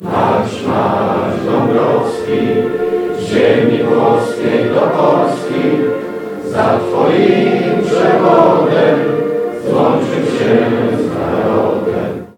Pierwsza sesja Rady Gminy Ełk rozpoczęła się hymnem państwowym.
hymn.mp3